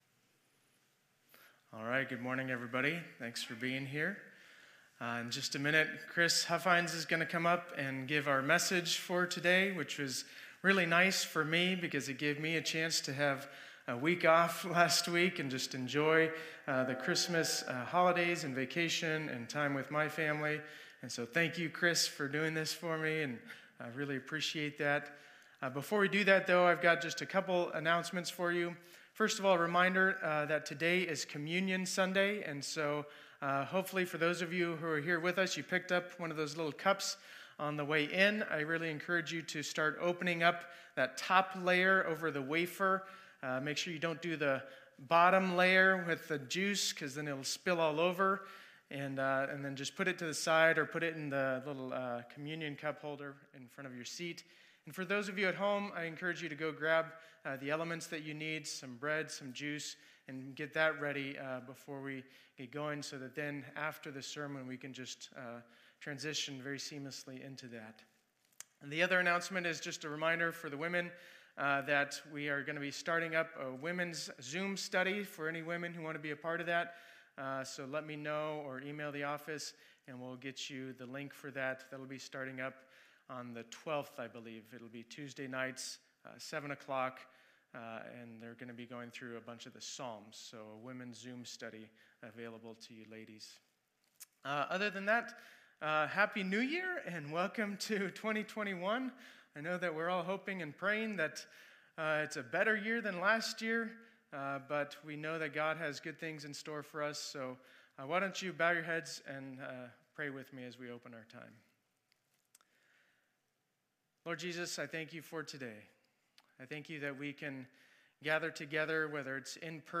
2021-01-03 Sunday Service Guest Speaker